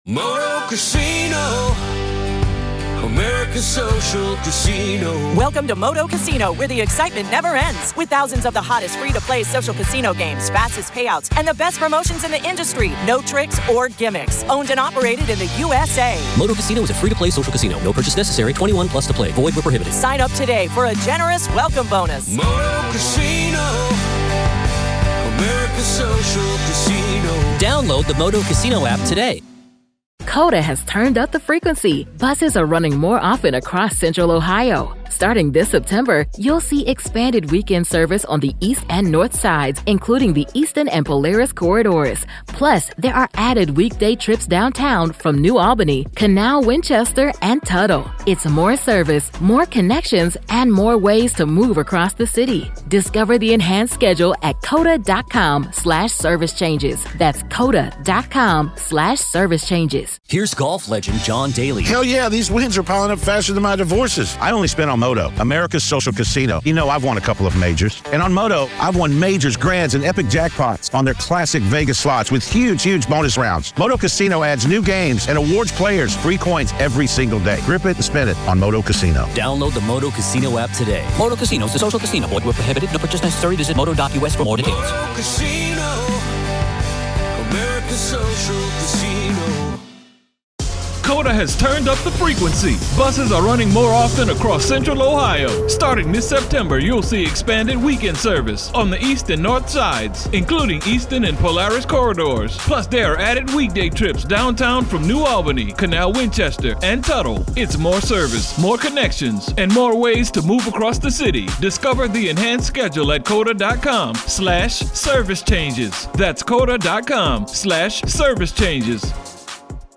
The Trial Of Lori Vallow Daybell Day 9 Part 2 | Raw Courtroom Audio